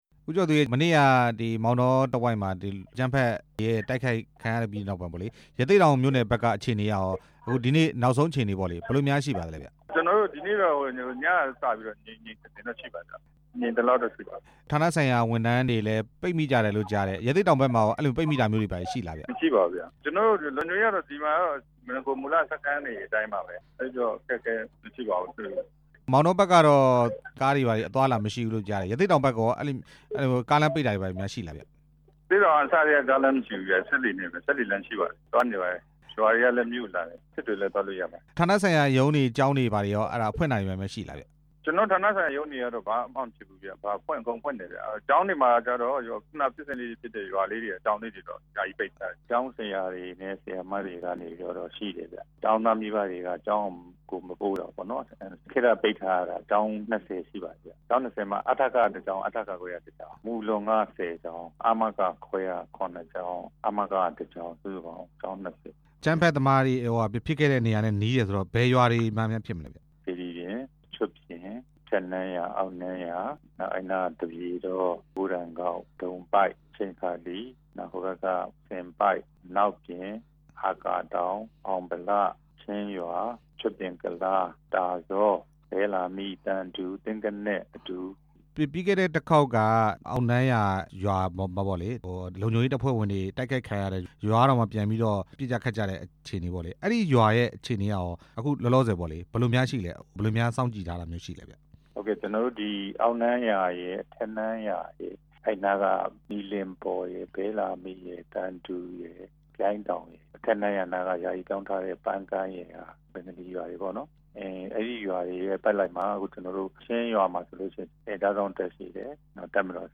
ရသေ့တောင်မှာ လုံခြုံရေးအရ စောင့်ကြည့်နေတဲ့အကြောင်း မေးမြန်းချက်